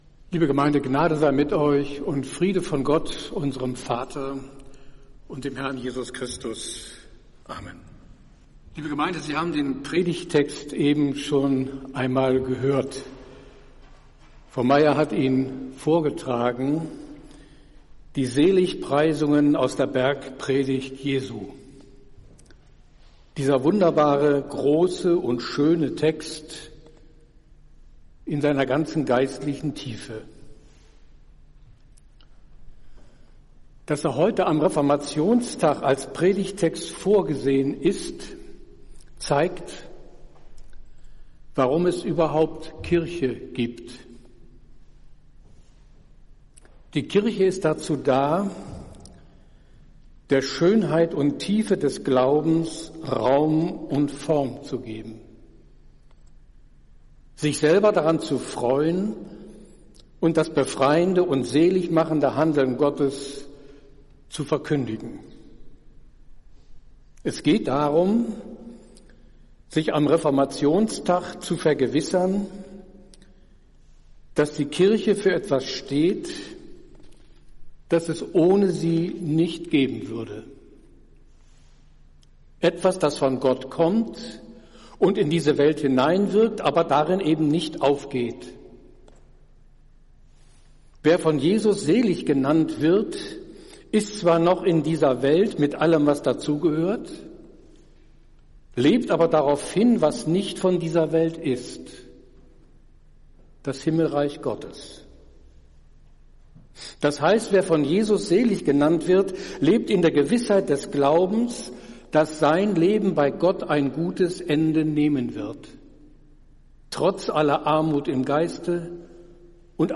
Predigt des Gottesdienstes zum Reformationstag 2023
Wir haben uns daher in Absprache mit der Zionskirche entschlossen, die Predigten zum Nachhören anzubieten.
Podcast-Predigt-Reformationstag.mp3